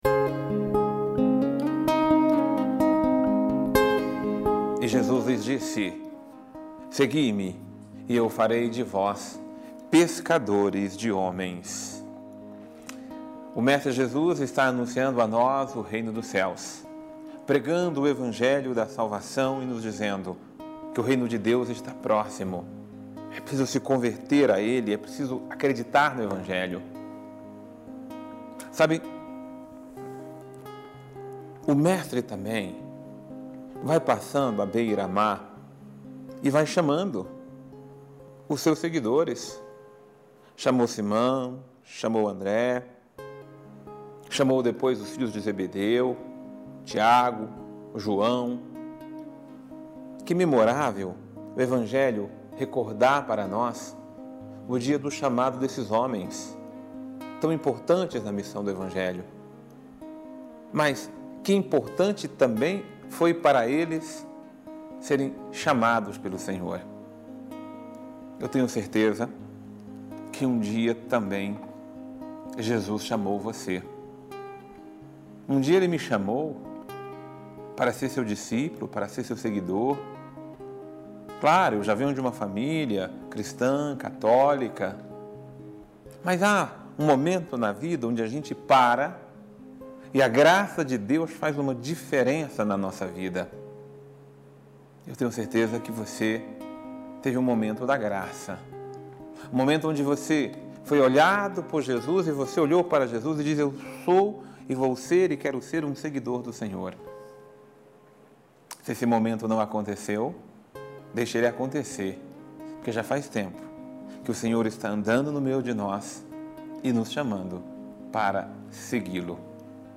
Homilia | A graça de Deus faz a diferença em nossa vida